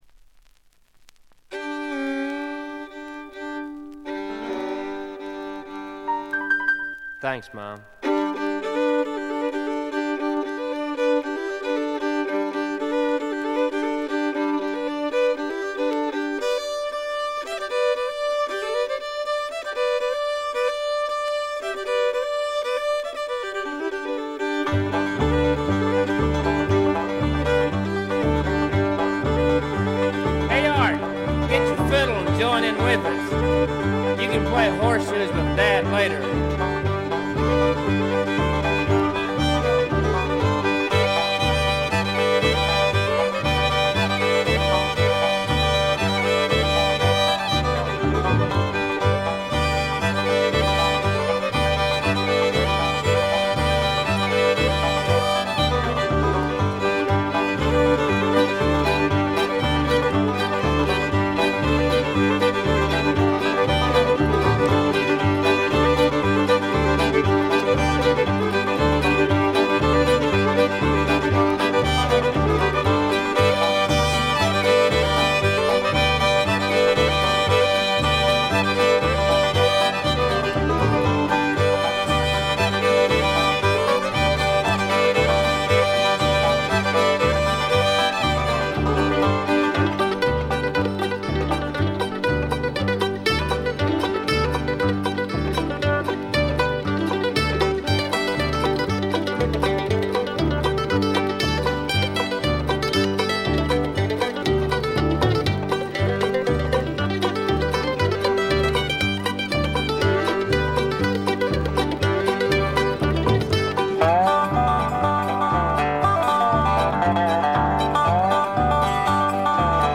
部分試聴ですがほとんどノイズ感無し。
エリア・コード615等で活躍した名フィドラ-。
試聴曲は現品からの取り込み音源です。
Violin, Viola, Mandola